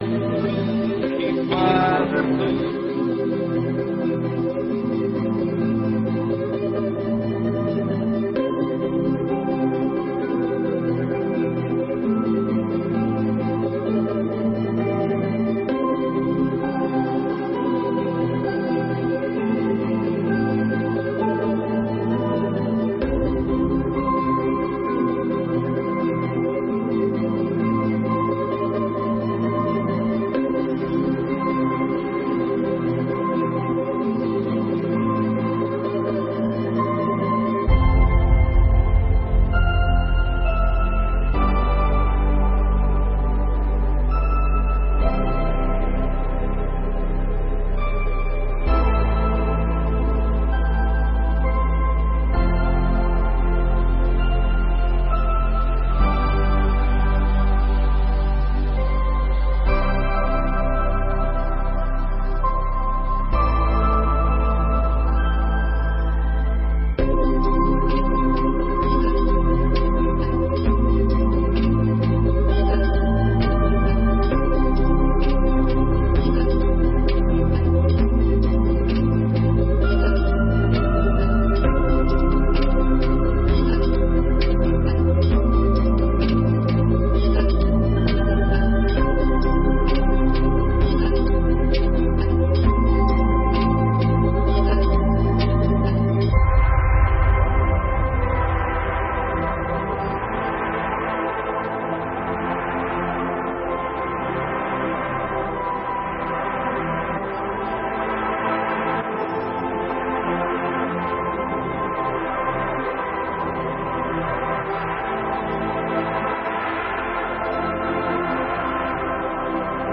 7ª Sessão Ordinária de 2021